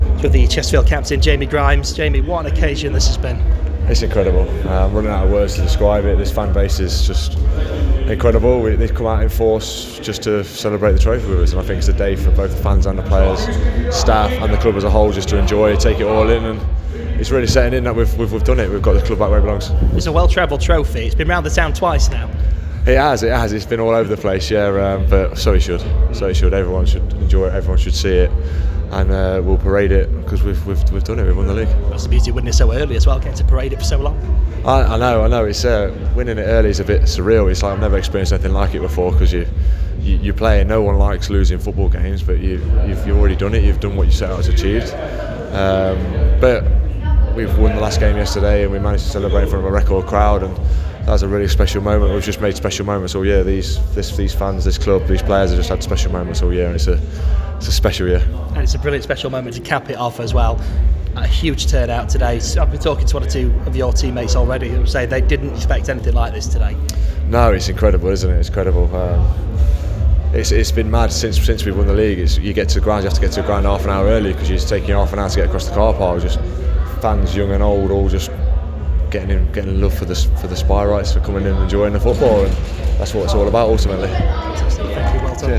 Chesterfield celebrated their return to the Football League with an open top bus parade which ended at the Town Hall as thousands of fans joined the team to share in the joy of their amazing National League triumph.